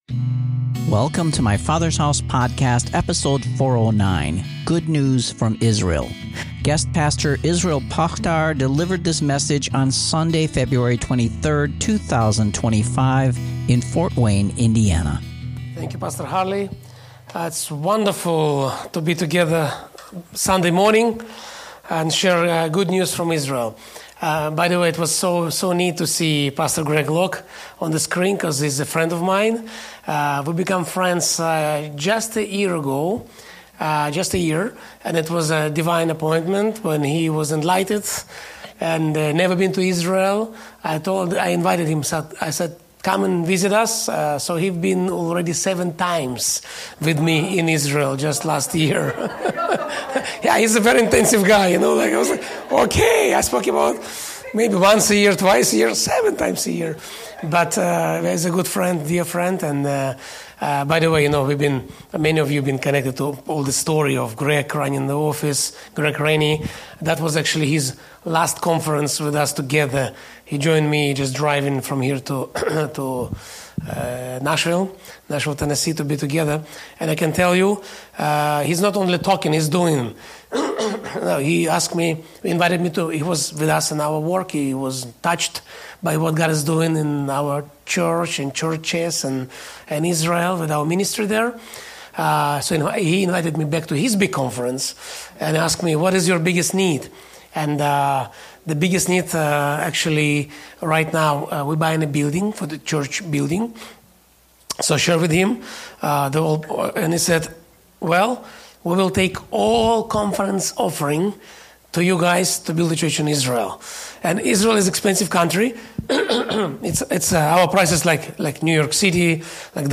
International guest minister